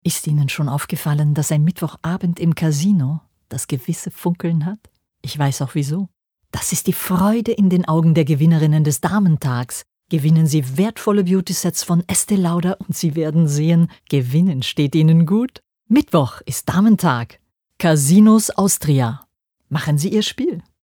WERBUNG" - Demo recording in German